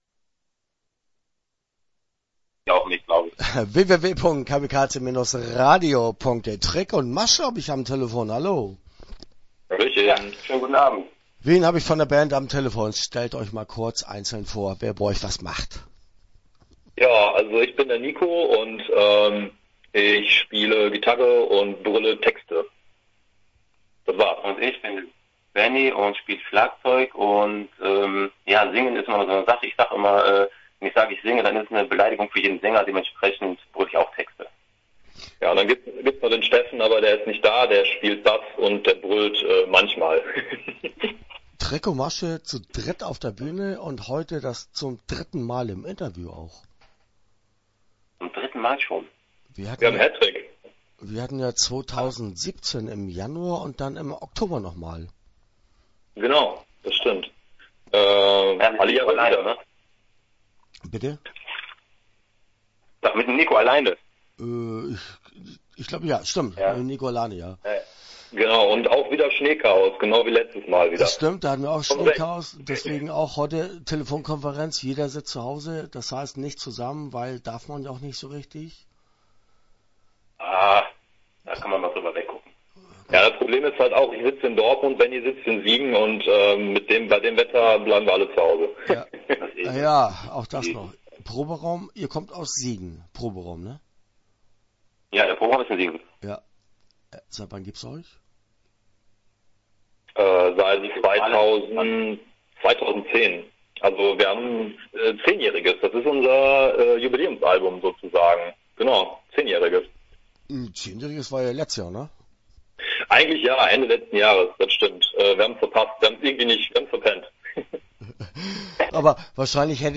Start » Interviews » Trick und Masche